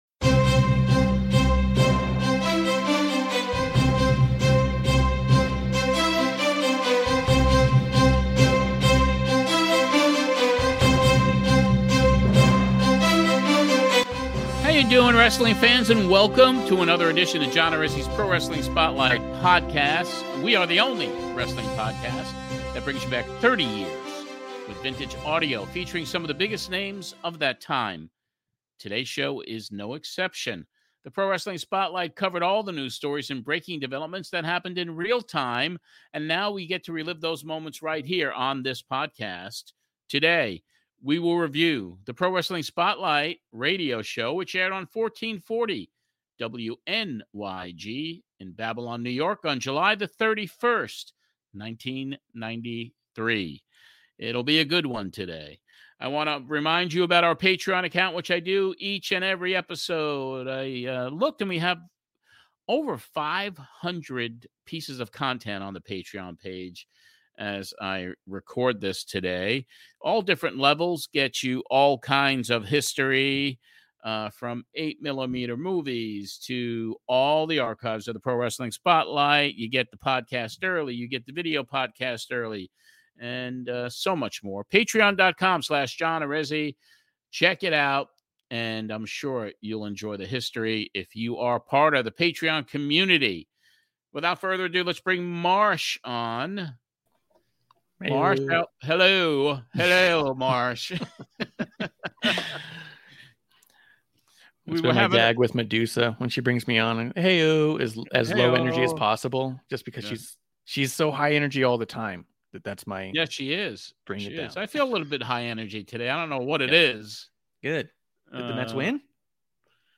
covering the July 31st, 1993 show, which originally aired on 1440 WNYG in Babylon, NY.
Terry Funk also appears on the show from 30 years ago, discussing his recent meetings with WCW about coming in to work with the creative team. Funk tells us why he turned the opportunity down.